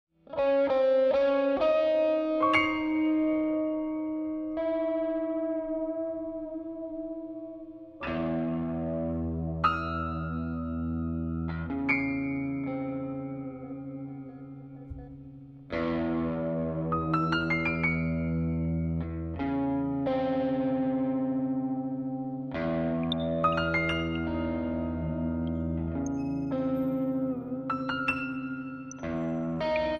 Free improvisation that stretches the limits